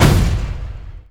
Wall.wav